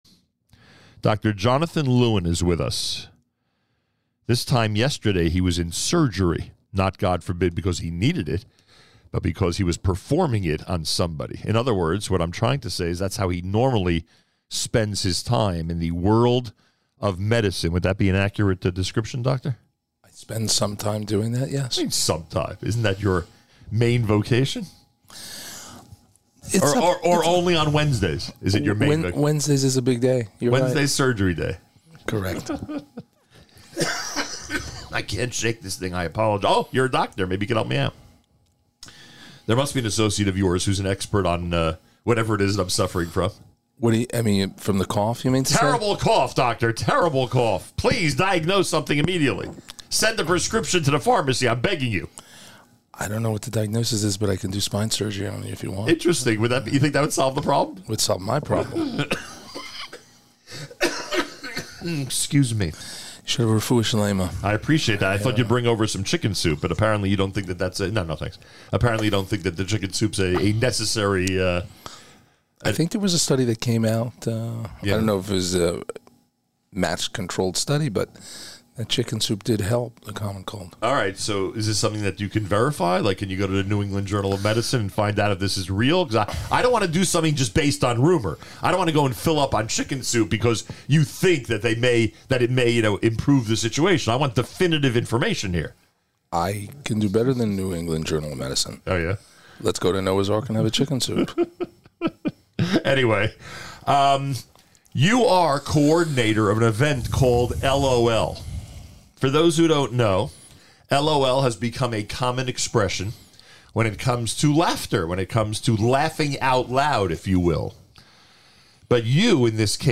to the studio during this morning’s JM in the AM to preview the 2/8 LOL (Law Orthopedics Laughter) Night of Comedy to benefit Geerz.